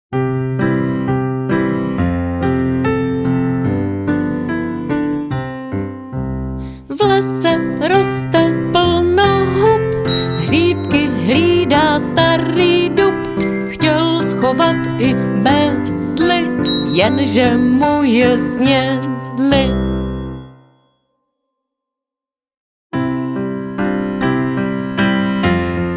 zhudebněných říkadel